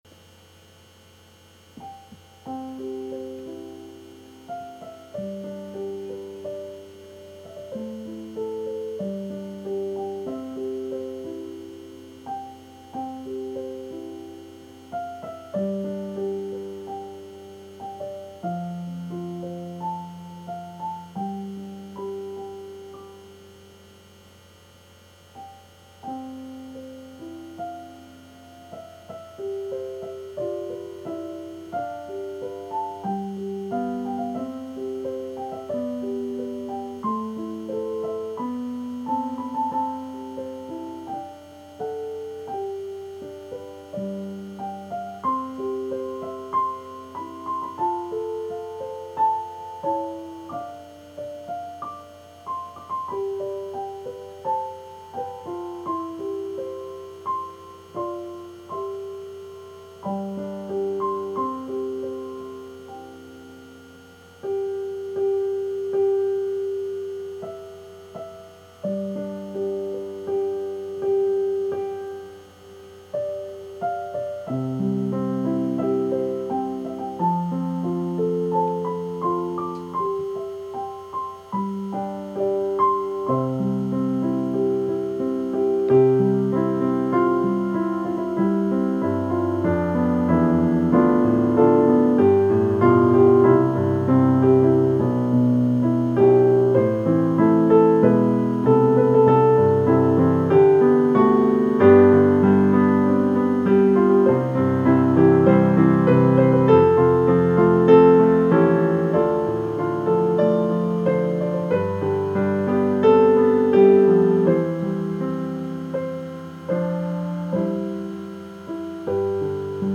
My favorite hymn is “It Is Well” written by Horatio G. Spafford.
These recordings are from my keyboard so that I don’t forget how they sound.
:) (I apologize for the buzzing of the microphone in both recordings and for my pauses in the first one, and the too-fast-tempo and wrong chords in the second recording)